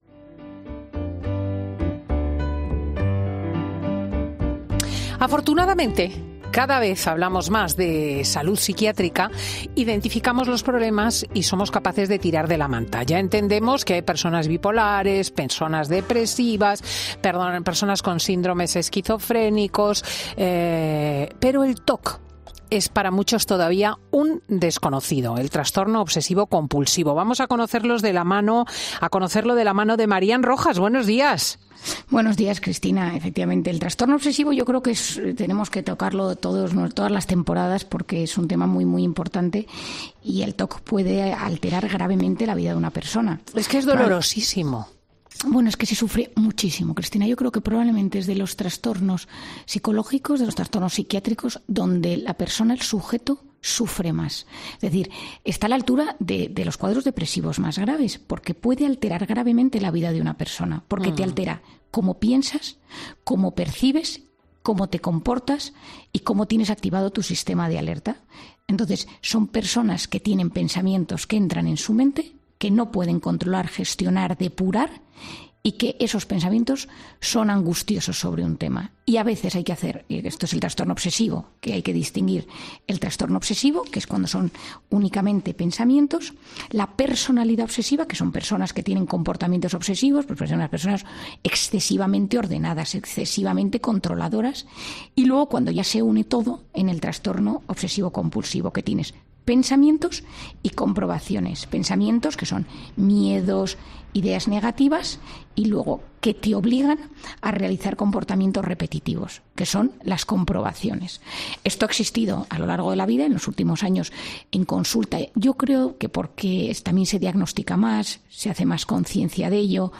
Lo ha explicado Marian Rojas en 'Fin de Semana' y ha dado las claves de cómo se puede tratar en terapia